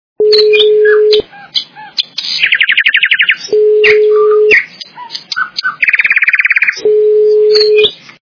Голоса птиц - Соловьиные трели Звук Звуки Голоси птахів - Соловїні треді
» Звуки » Природа животные » Голоса птиц - Соловьиные трели
При прослушивании Голоса птиц - Соловьиные трели качество понижено и присутствуют гудки.